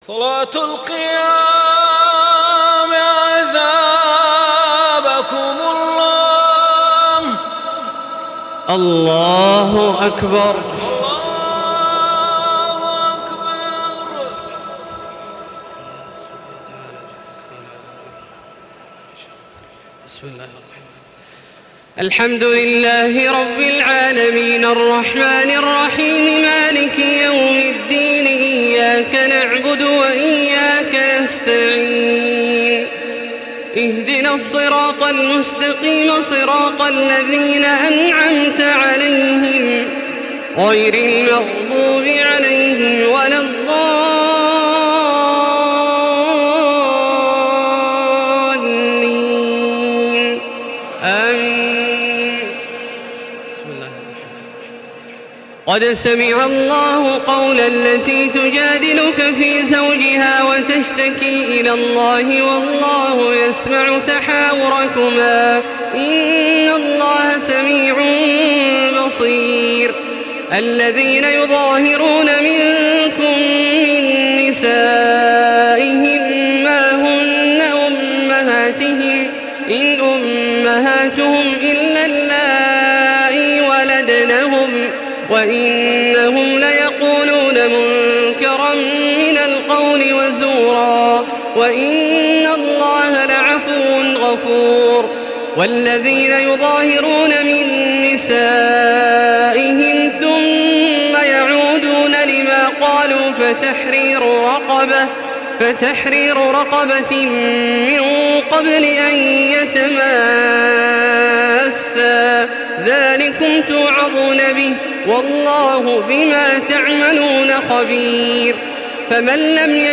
تراويح ليلة 27 رمضان 1435هـ من سورة المجادلة الى الصف Taraweeh 27 st night Ramadan 1435H from Surah Al-Mujaadila to As-Saff > تراويح الحرم المكي عام 1435 🕋 > التراويح - تلاوات الحرمين